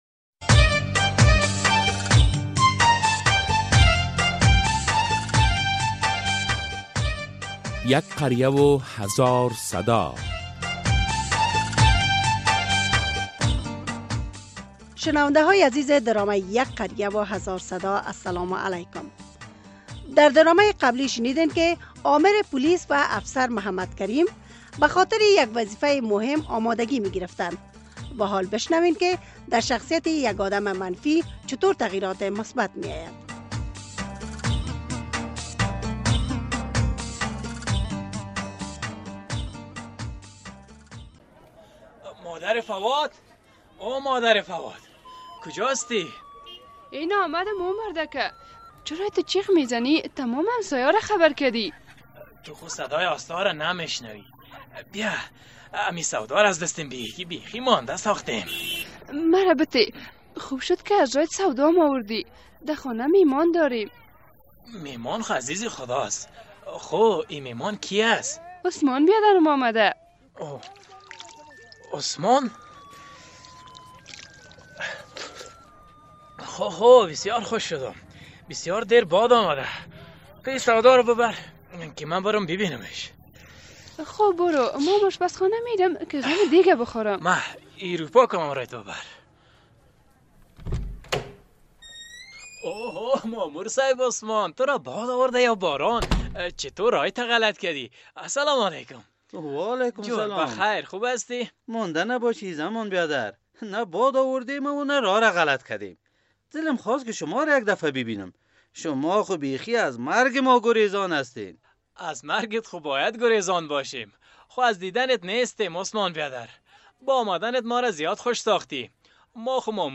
درامه یک قریه هزار صدا قسمت ۲۰۸